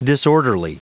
Prononciation du mot disorderly en anglais (fichier audio)
Prononciation du mot : disorderly